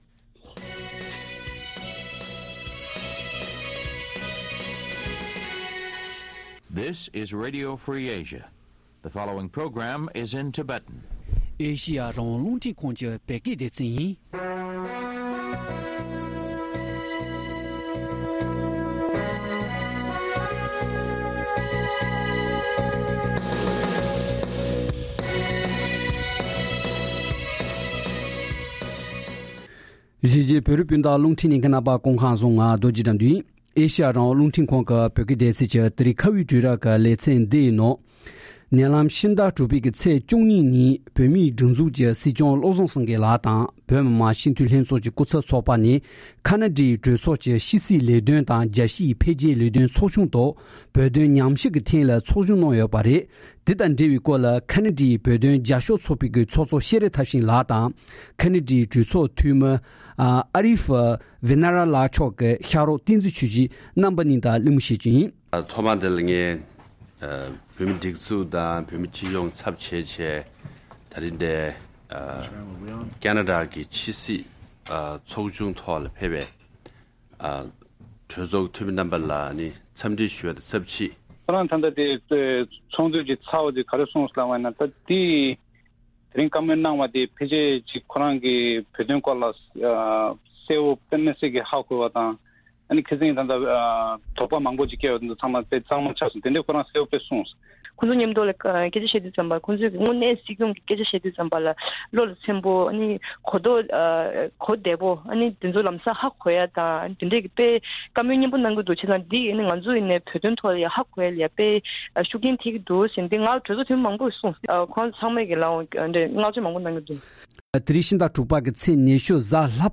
བཅར་འདྲི་ཞུས་པར་ཉན་རོགས་ཞུ་